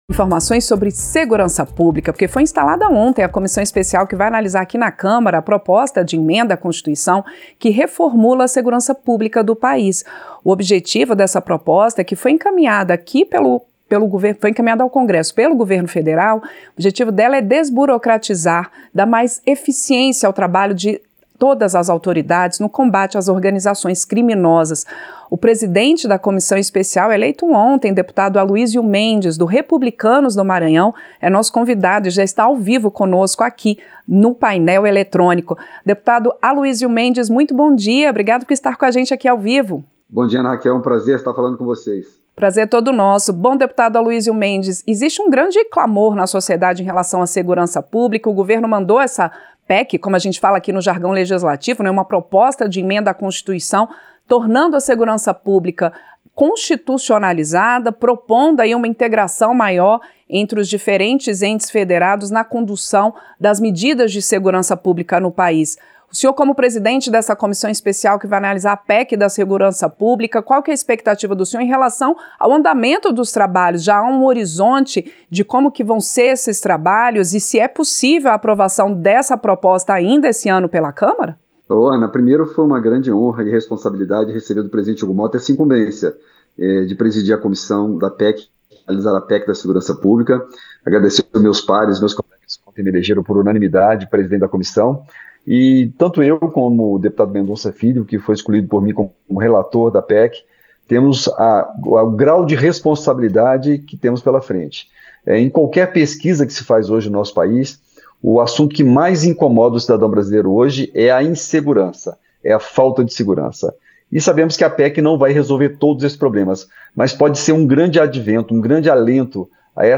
Entrevista - Dep. Aluisio Mendes (Republicanos-MA)